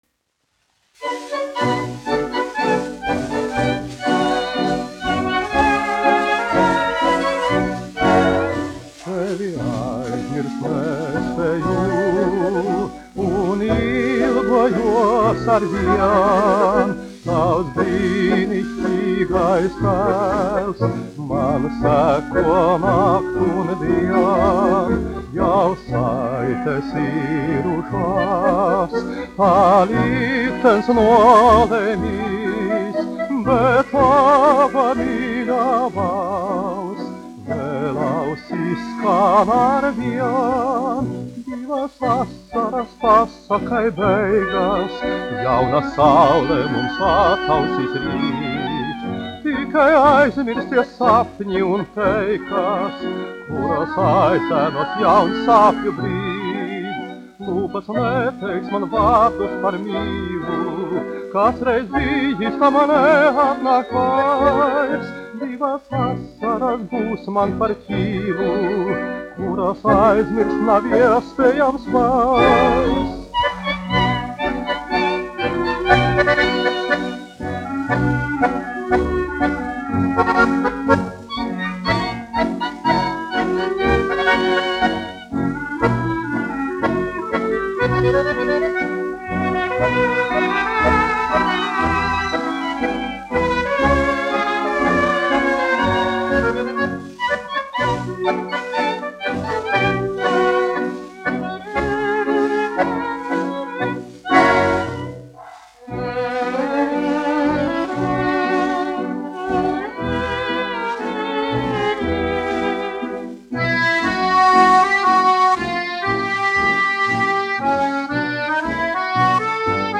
1 skpl. : analogs, 78 apgr/min, mono ; 25 cm
Populārā mūzika
Skaņuplate
Latvijas vēsturiskie šellaka skaņuplašu ieraksti (Kolekcija)